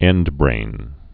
(ĕndbrān)